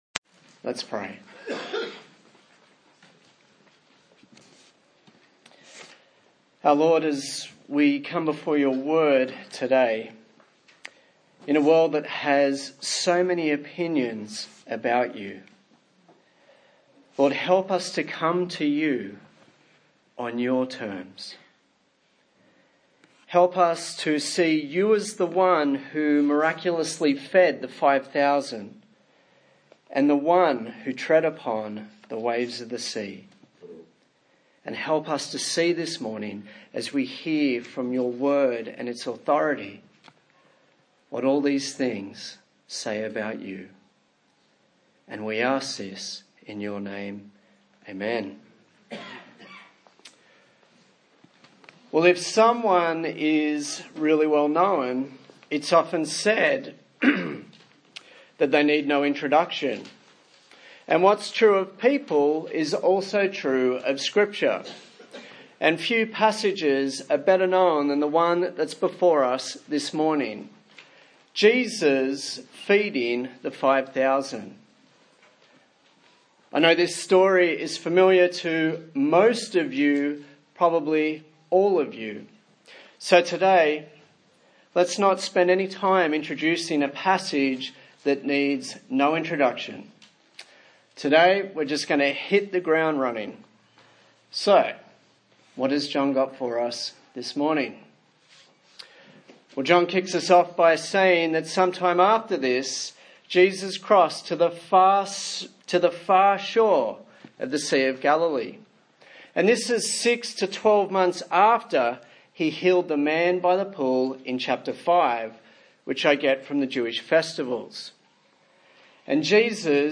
John Passage: John 6:1-24 Service Type: Sunday Morning